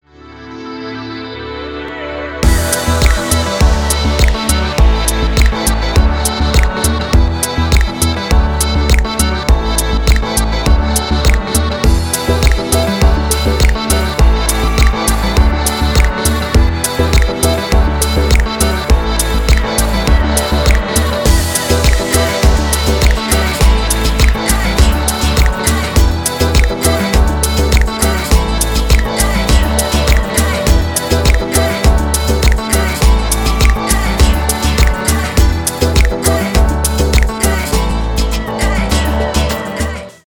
house
без слов , танцевальные